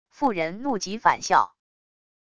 妇人怒极反笑wav音频